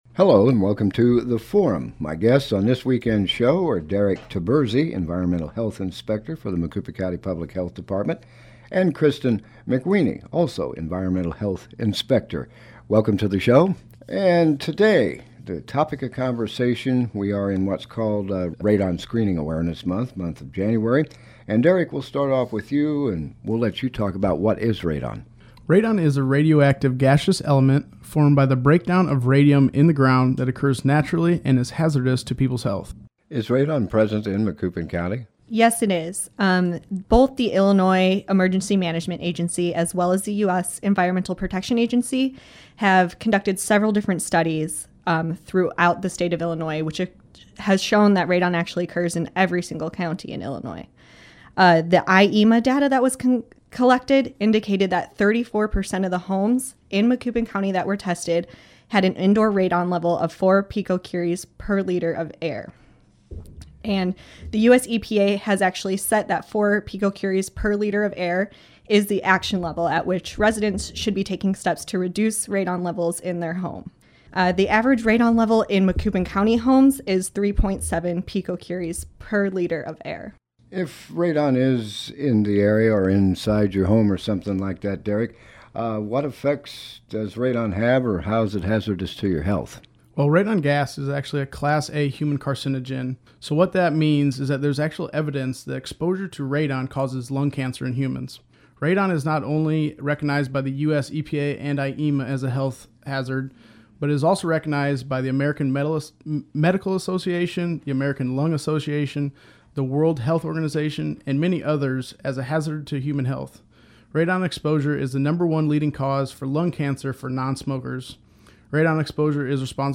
Environmental Health Inspector